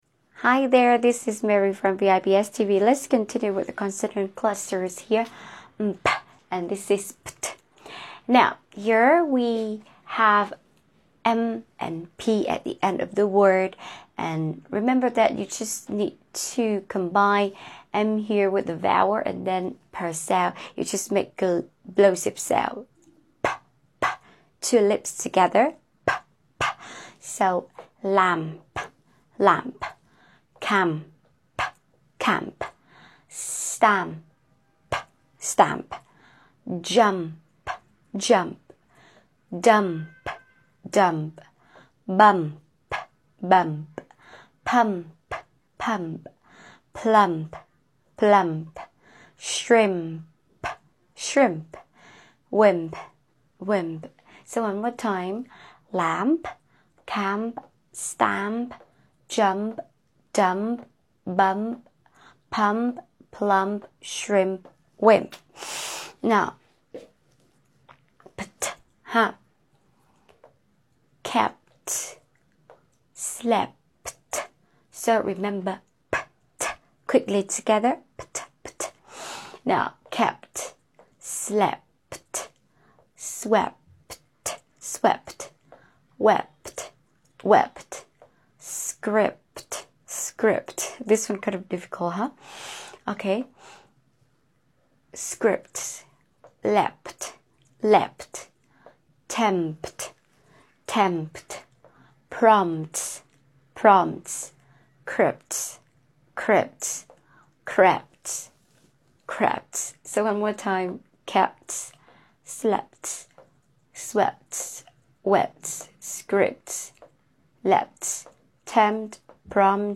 How to pronounce pt and mp sounds in english | consonant blend | consonant cluster | ipa